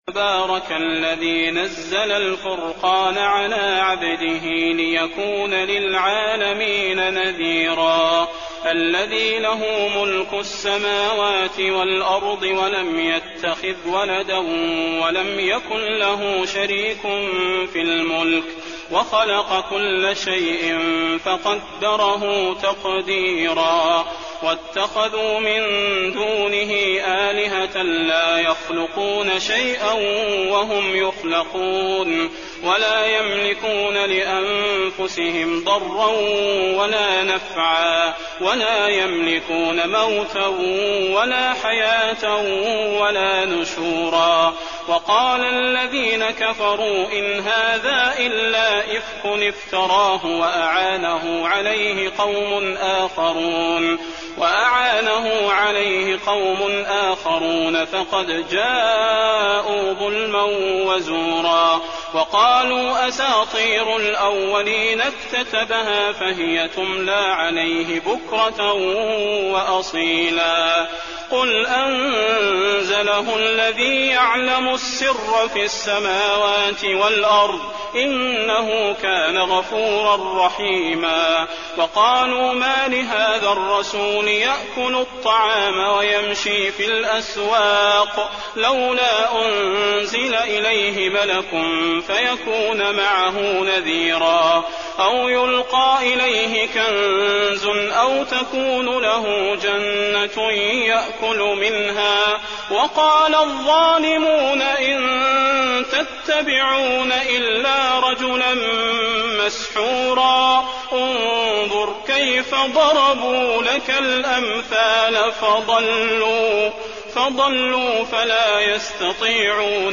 المكان: المسجد النبوي الفرقان